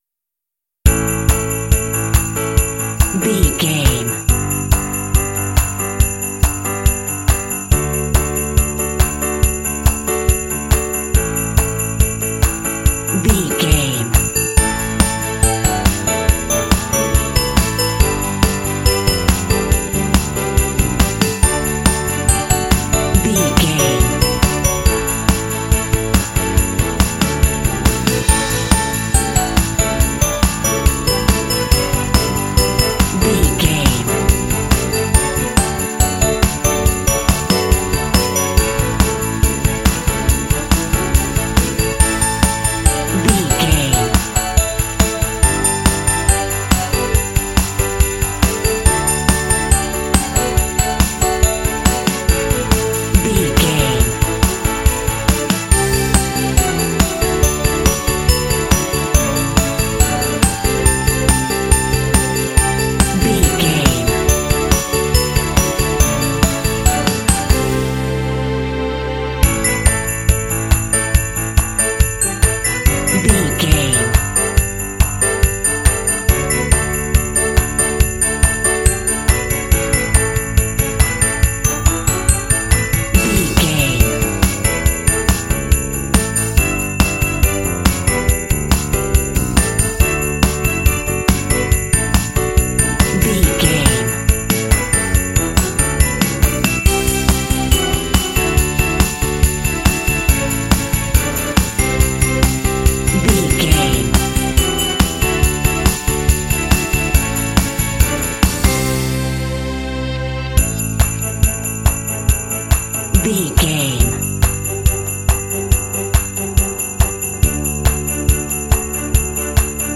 Ionian/Major
happy
uplifting
bouncy
festive
piano
drums
bass guitar
electric guitar
strings
contemporary underscore